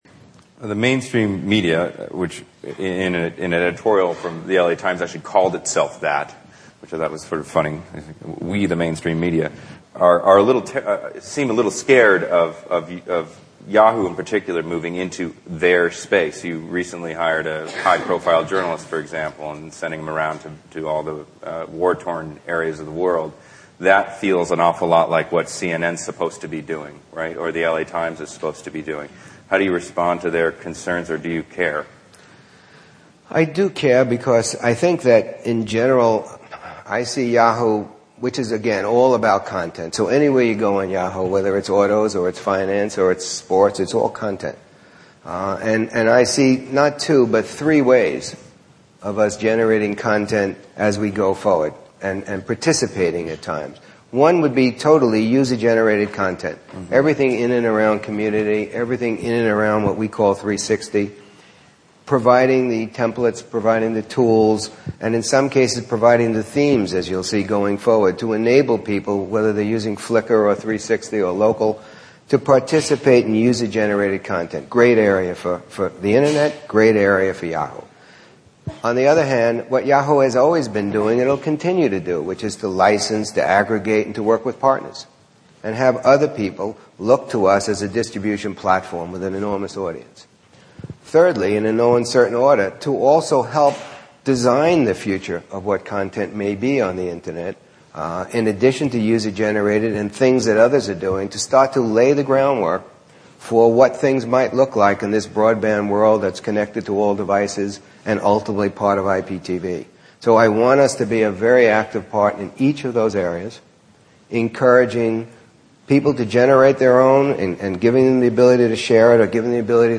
Interview of Terry Semel of Yahoo This is an excerpt from an interview of Terry Semel, CEO at Yahoo.